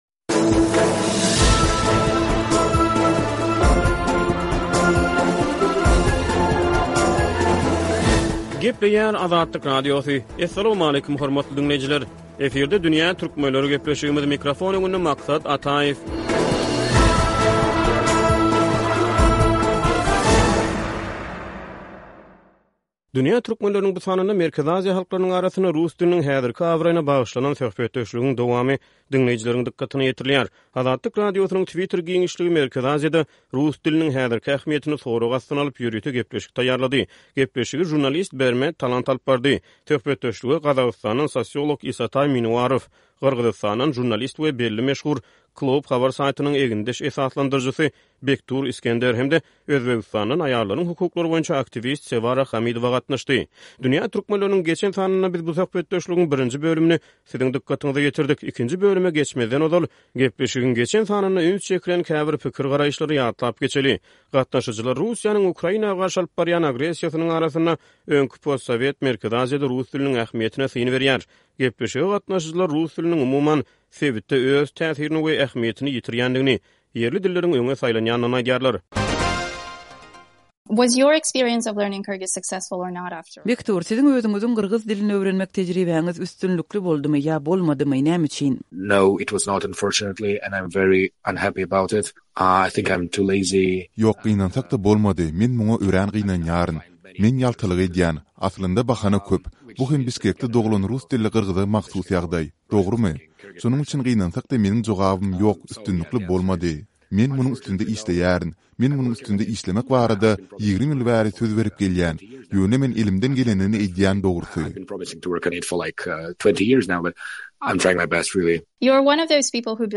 Dünýä Türkmenleriniň bu sanynda Merkezi Aziýa halklarynyň arasynda rus diliniň häzirki abraýyna bagyşlanan söhbedetşligiň dowamy diňleýjileriň dykgatyna ýetirilýär.